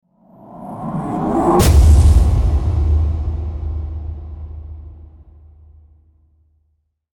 Suspenseful Rise And Deep Impact Sound Effect
Description: Suspenseful rise and deep impact sound effect.
Build powerful tension and a cinematic atmosphere using bold audio design. Ideal for movies, games, and media projects that need a strong, impactful hit.
Suspenseful-rise-and-deep-impact-sound-effect.mp3